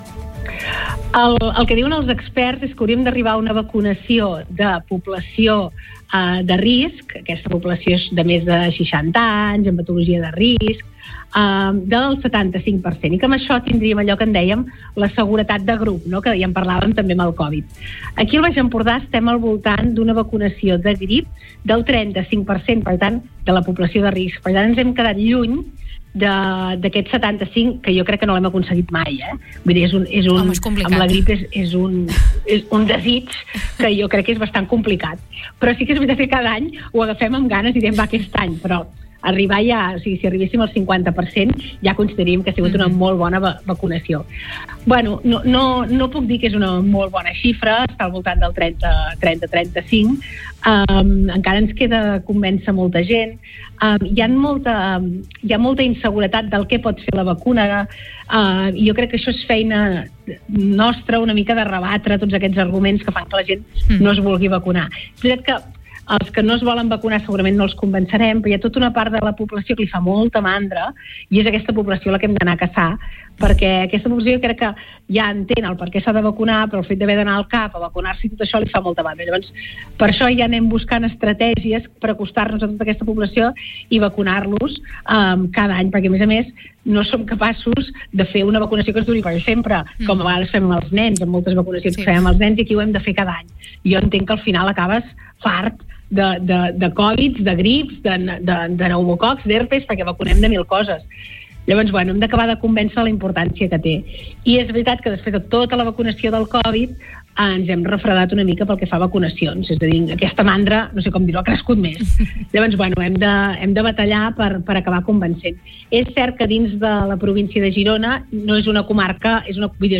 Entrevistes Supermatí
Supermatí - entrevistes